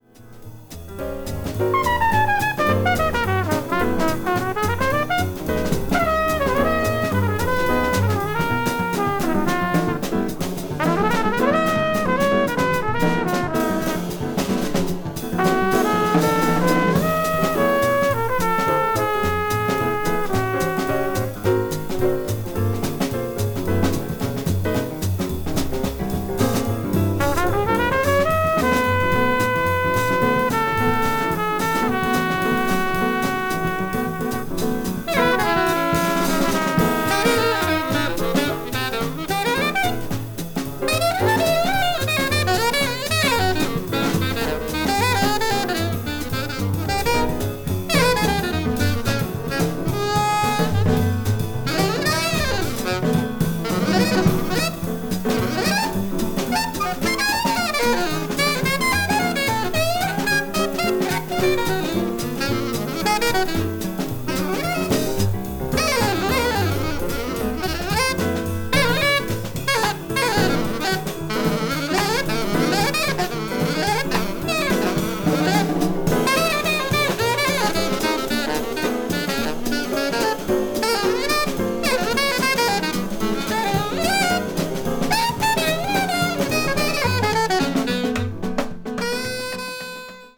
media : EX-/EX-(わずかなチリノイズ/一部軽いチリノイズが入る箇所あり)
modern jazz   post bop